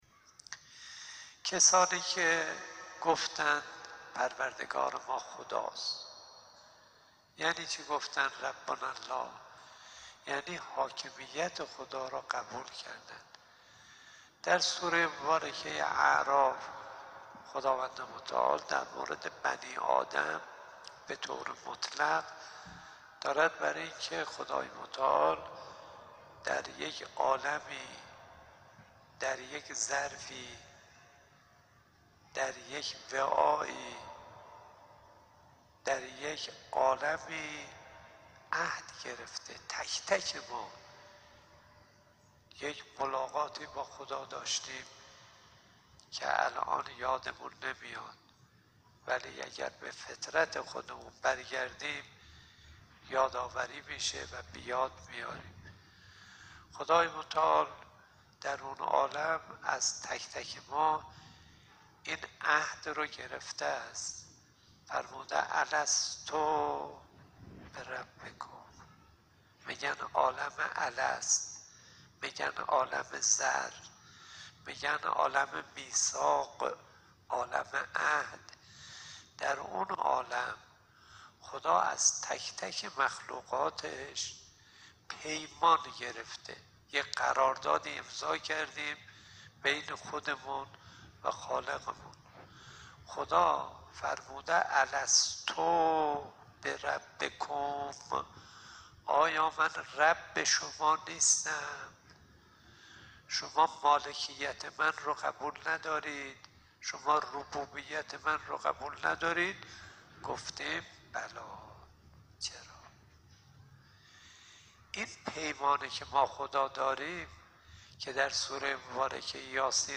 به گزارش خبرنگار خبرگزاری رسا، آیت‌الله کاظم صدیقی امام جمعه موقت تهران، شب گذشته در جلسه اخلاقی و معرفتی ماه مبارک رمضان در مسجد شهید بهشتی تهران به ایراد سخنرانی پرداخت و گفت: انسان اگر خود را در محضر خداوند و اولیای الهی بداند از انجام کار خوب غفلت نمی کند.